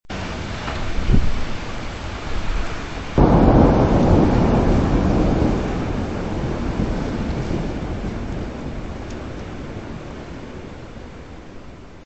Temporale con tuoni
Suono del temporale, acquazzone, pioggia con tuono in lontananza non troppo fragoroso.
THUNDER2.mp3